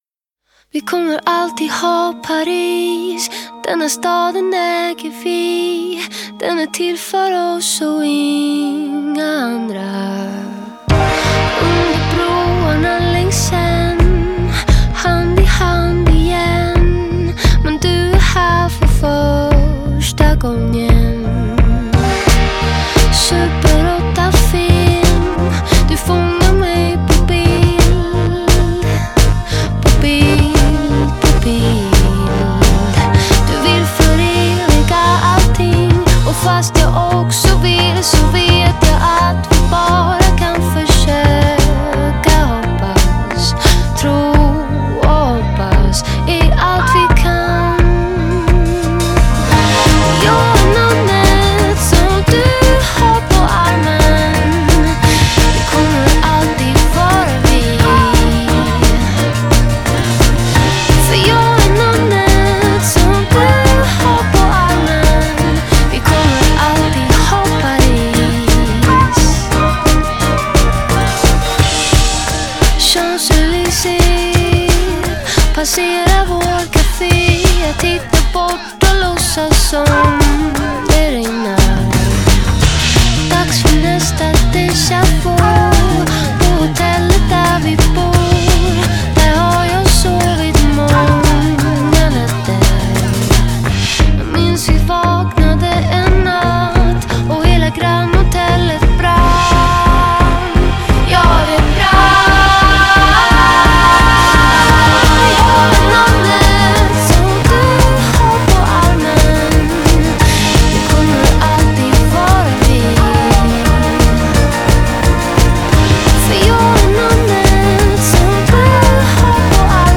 pop record